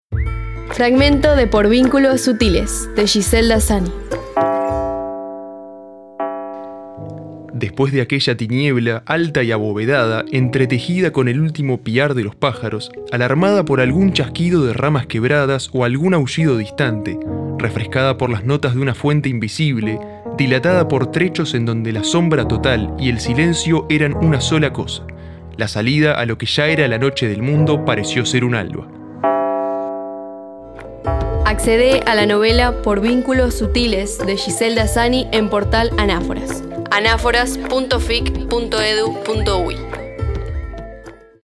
Esta serie recupera fragmentos de obras literarias, entrevistas, citas y conferencias; a través de textos y narraciones con las voces de poetas, periodistas y académicos que integran el repositorio.